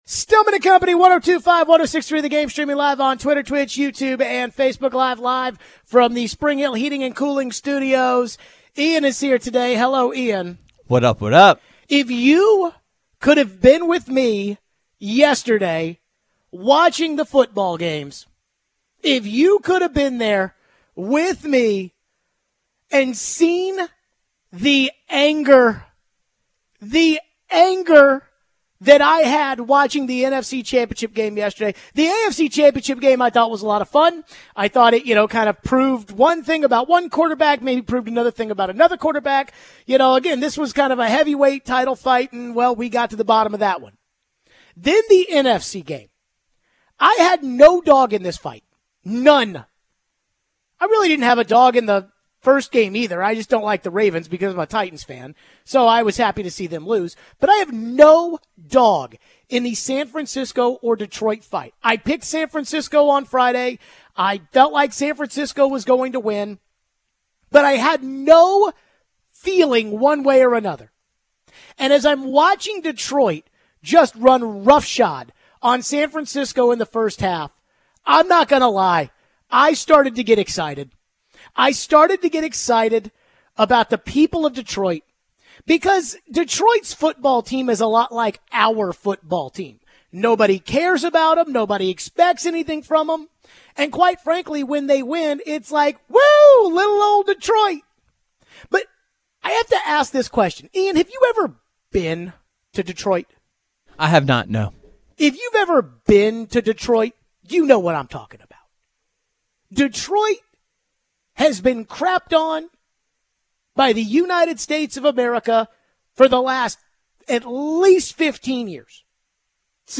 We take your phones. Plus, how much does Purdy have to prove in the final game?